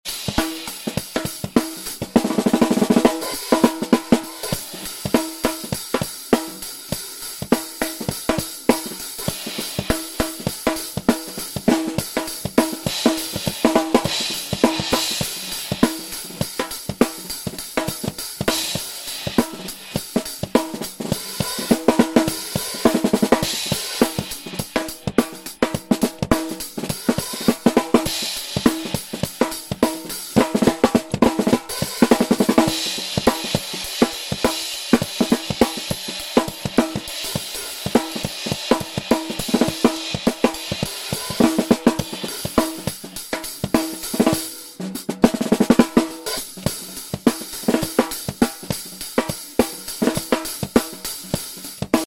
Cymbales ZULTAN DUNE sound effects free download